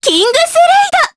Mediana-Vox_Kingsraid_jp_b.wav